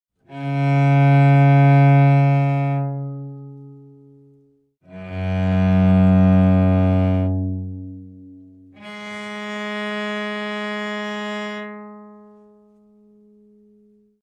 Так поет виолончель